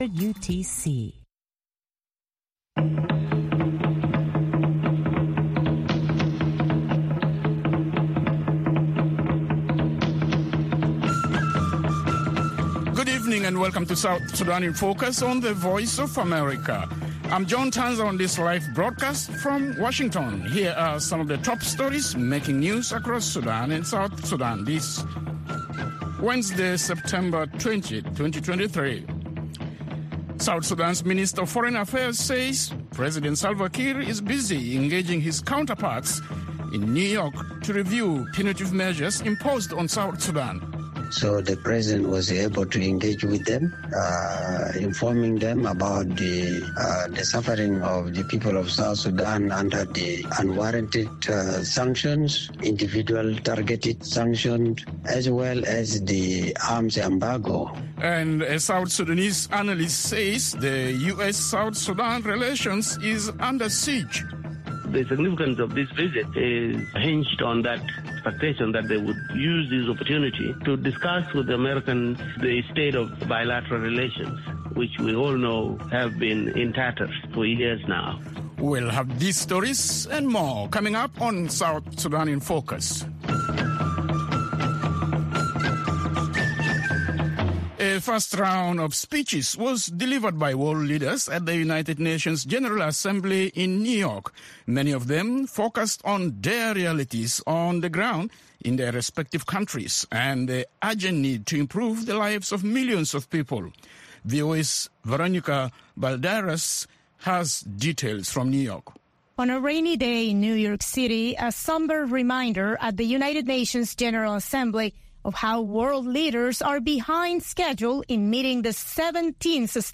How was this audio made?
South Sudan in Focus airs at 7:30 pm in Juba (1630 UTC) and can be heard on FM stations throughout South Sudan, on shortwave, and on VOA’s 24-hour channel in Nairobi at 8:30 pm.